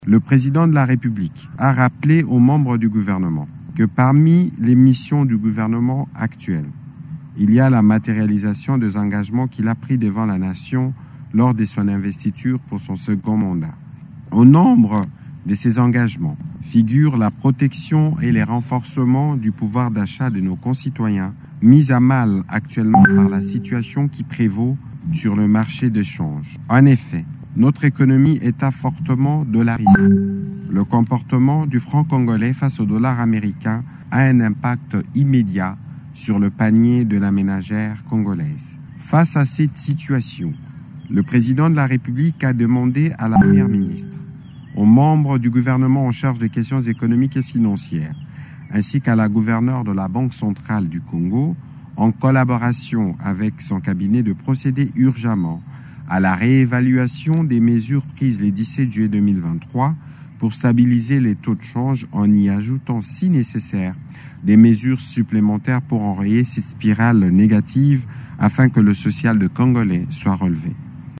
Parmi ces mesures figurent « la protection et le renforcement du pouvoir d’achat des Congolais, mis en mal actuellement par la situation qui prévaut sur le marché de change », a indiqué le porte-parole du Gouvernement, Patrick Muyaya.